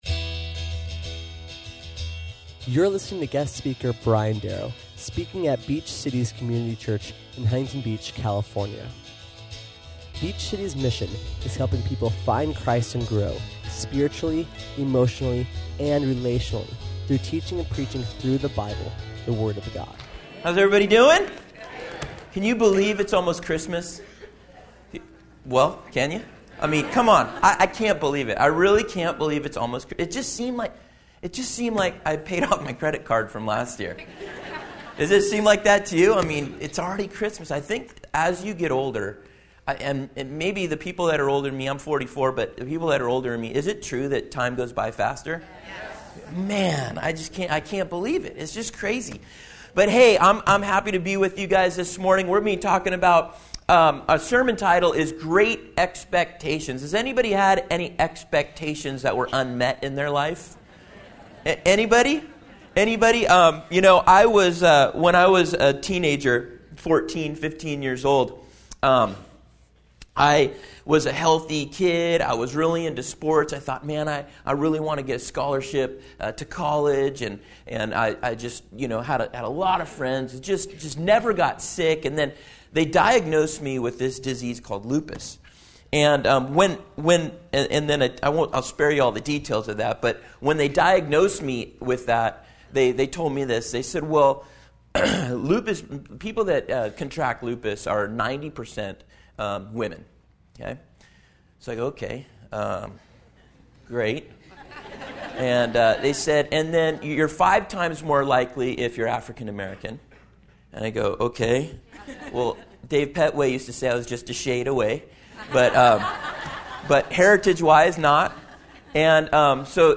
Listen or watch as we learn about what unmet expectations lead too and then how we have correct expectations and ultimately what that will lead to. SERMON AUDIO: SERMON NOTES: